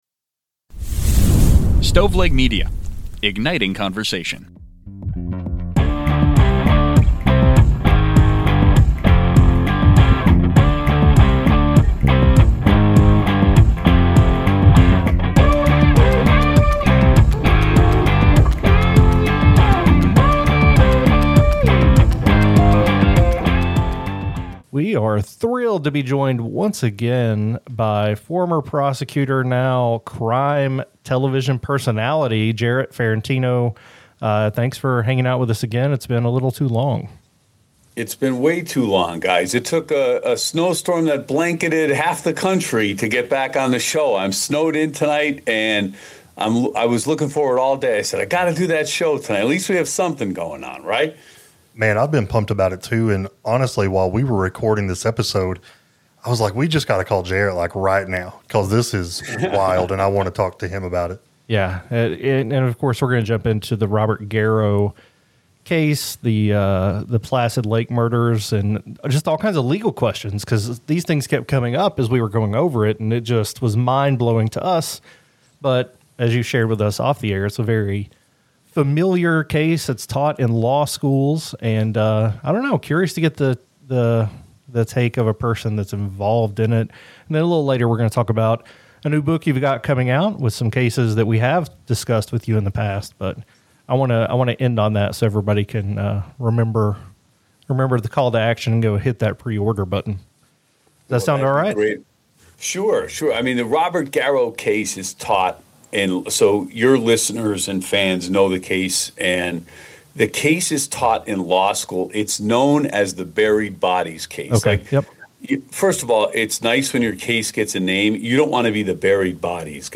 Buried Bodies and Books about Mothers - Interview